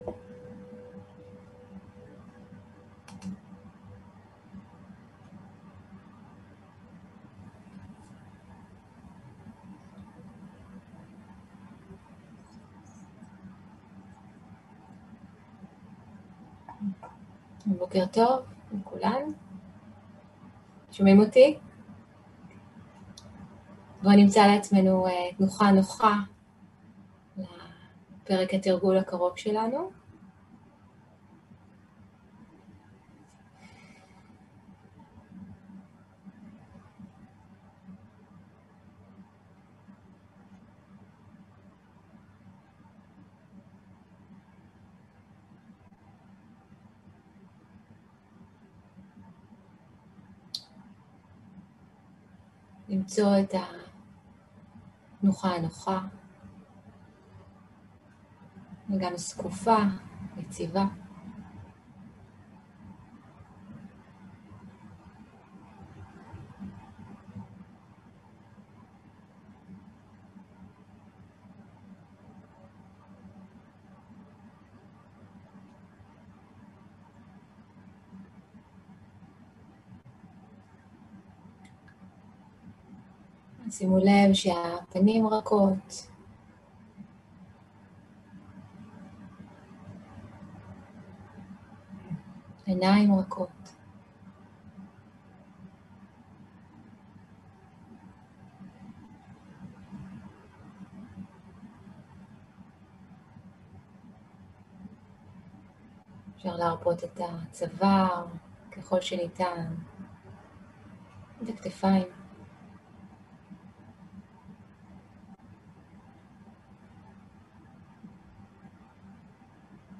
Guided meditation שפת ההקלטה